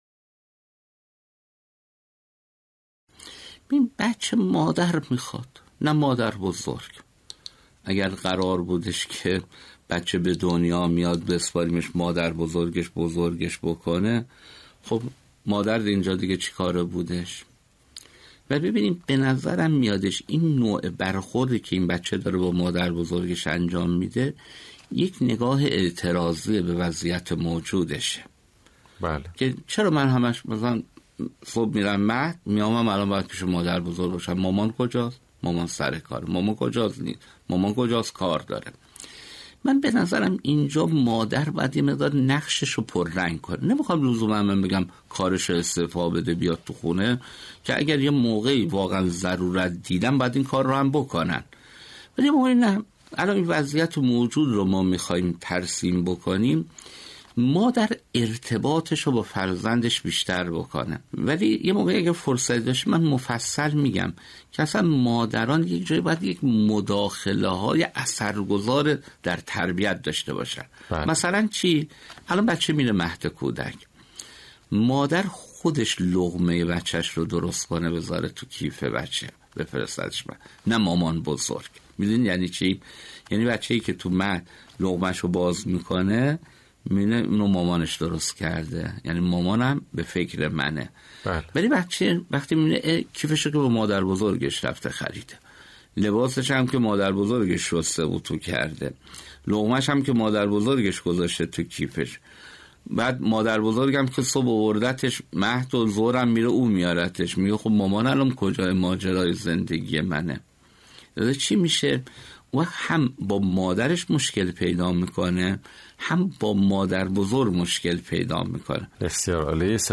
کارشناس خانواده و تربیت فرزند در پرسش و پاسخی